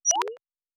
pgs/Assets/Audio/Sci-Fi Sounds/Interface/Data 24.wav